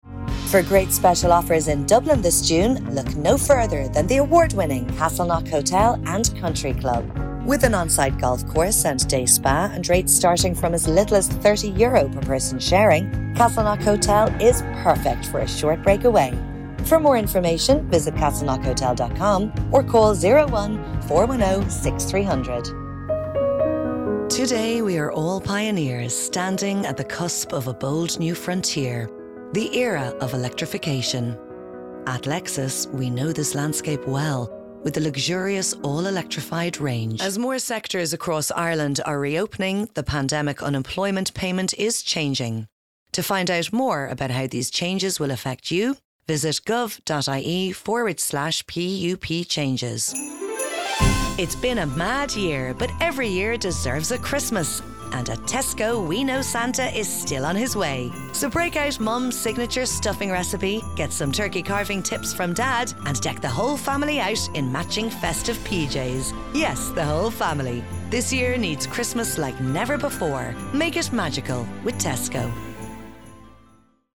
Female
Neumann mic, DBX286S processor/Scarlett focusrite 212
30s/40s, 40s/50s
Irish Dublin Neutral, Irish Neutral